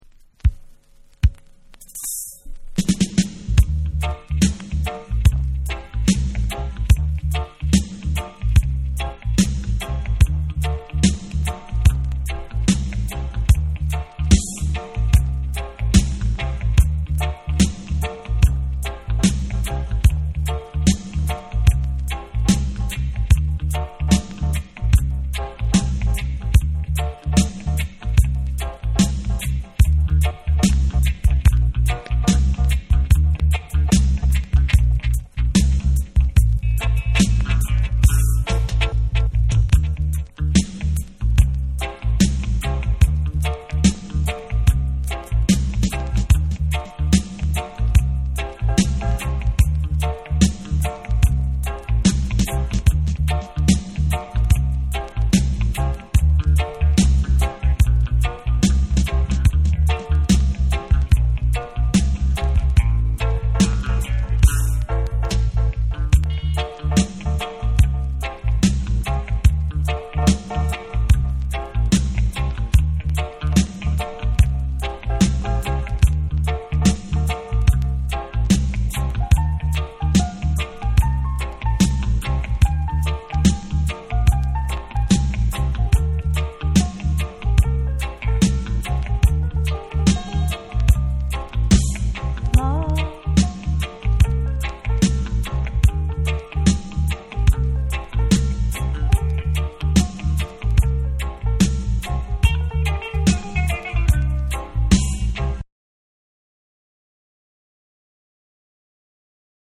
カップリングにはディープなダブ・ヴァージョンを収録。両面ダブ・ブレイクビーツとしても楽しめる1枚。
REGGAE & DUB